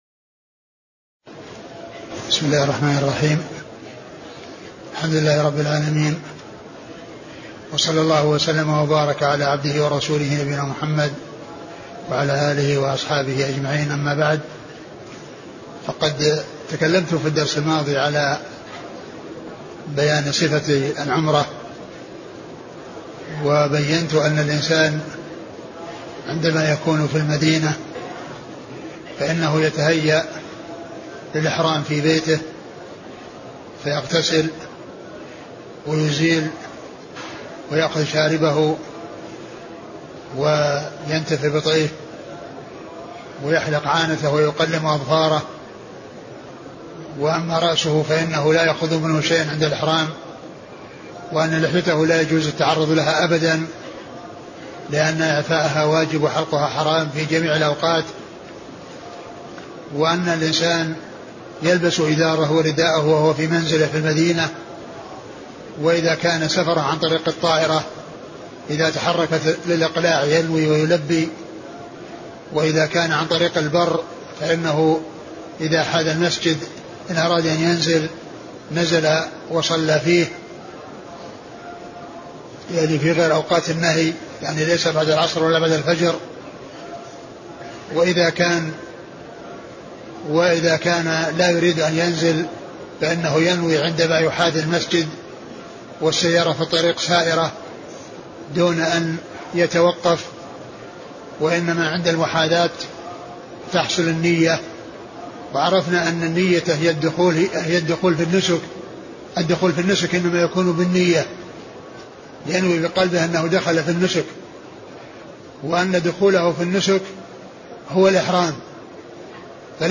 محاضرة صفة العمرة
تاريخ النشر ٢٢ ذو القعدة ١٤٢٧ المكان: المسجد النبوي الشيخ: فضيلة الشيخ عبدالمحسن بن حمد العباد البدر فضيلة الشيخ عبدالمحسن بن حمد العباد البدر صفة العمرة